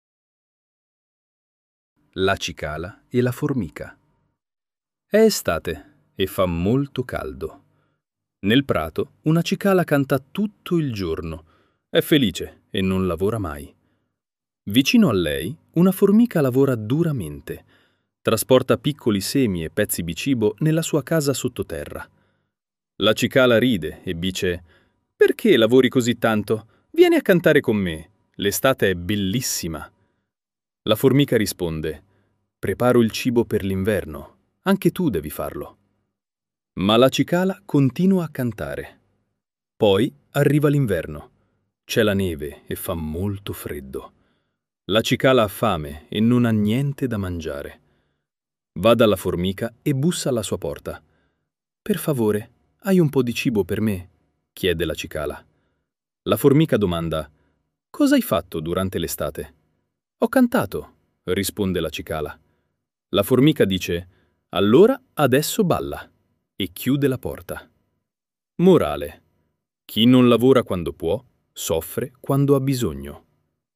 Audiolibro narrato da IA.